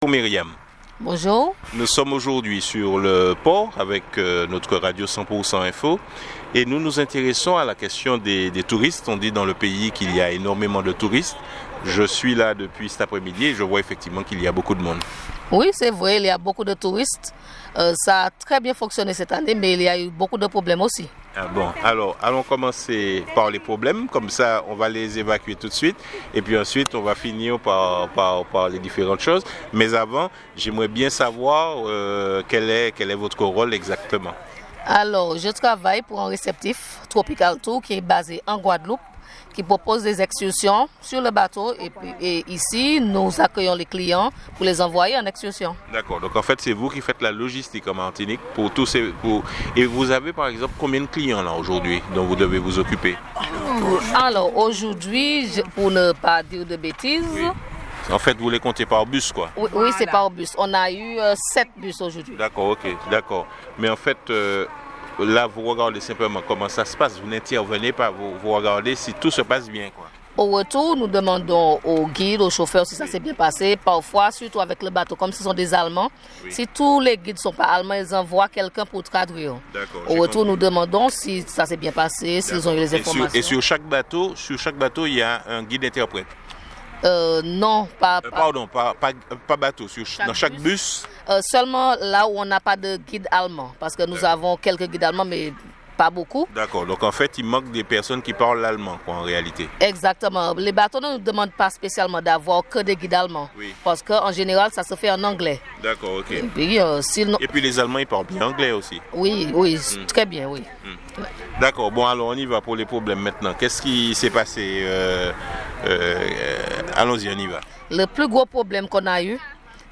Nous avons réalisé un reportage sur le terminal de la Pointe Simon.